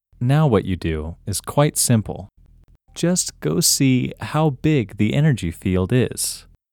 OUT – English Male 5